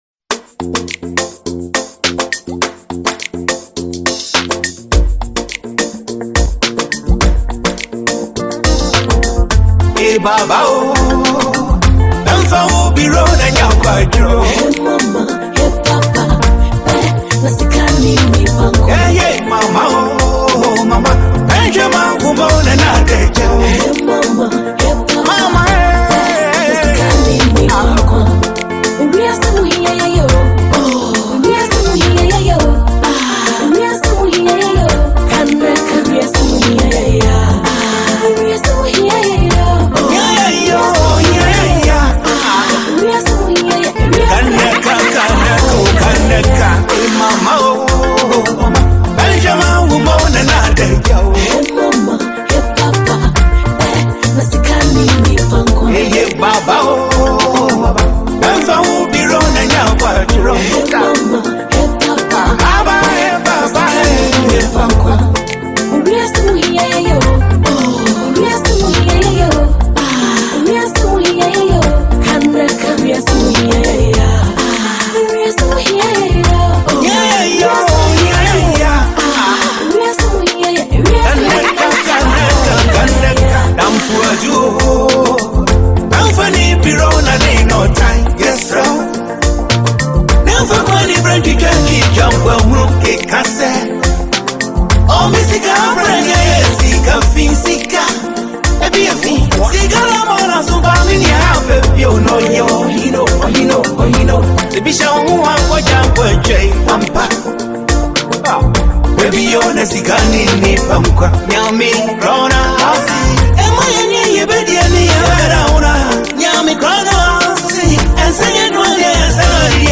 Ghanaian highlife
With its captivating beat and unforgettable chorus